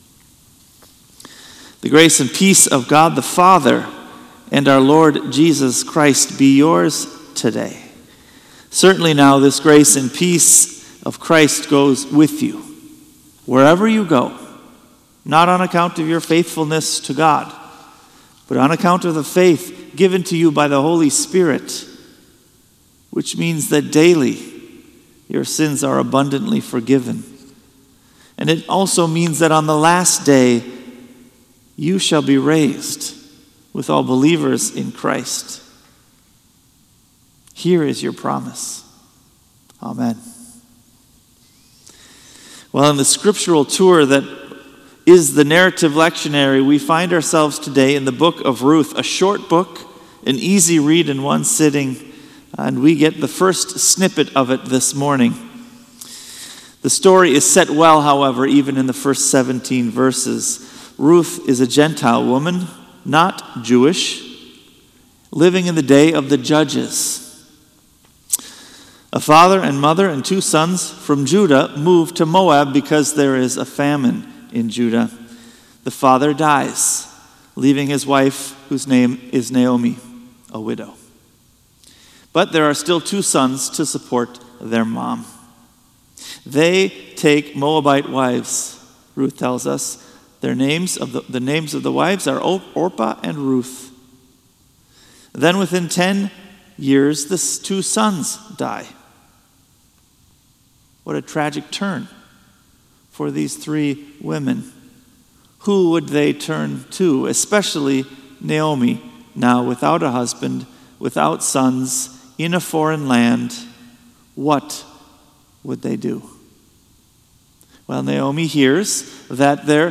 Sermon “Where You Go I Will Go” | Bethel Lutheran Church